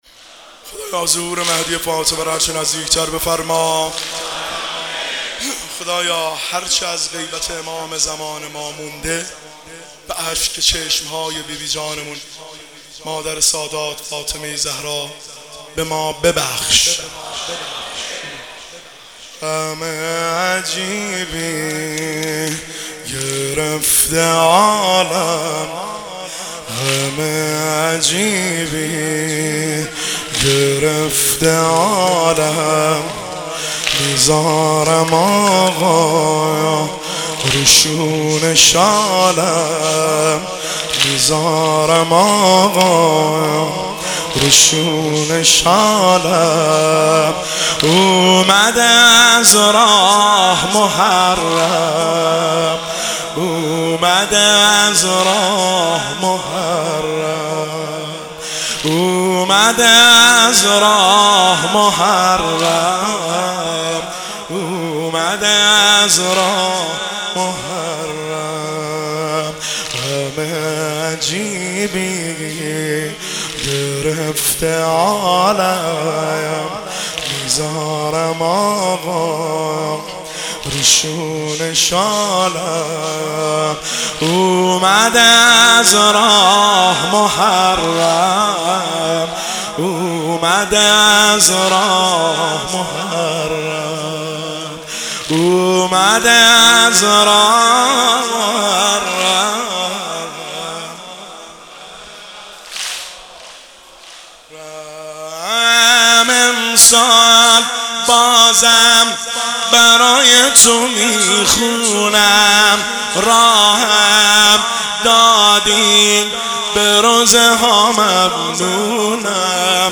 مداحی واحد شب سوم محرم